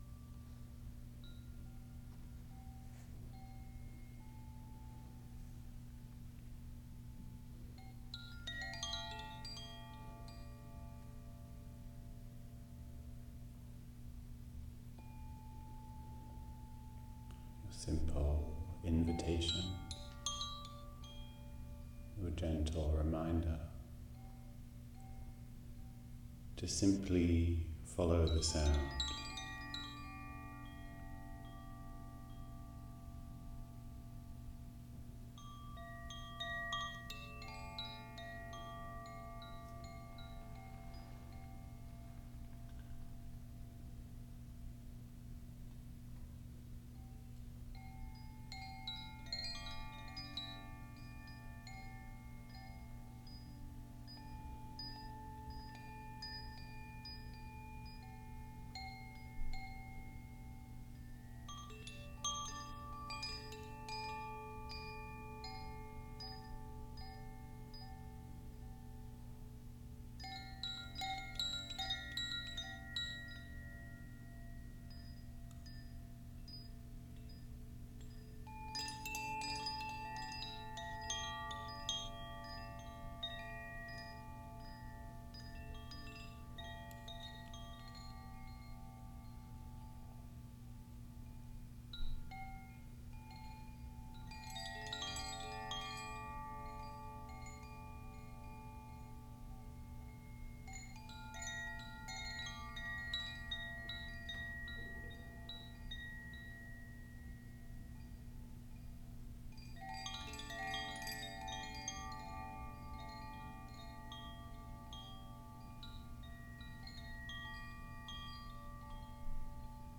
SoundoftheTimes-Sound-Meditation.m4a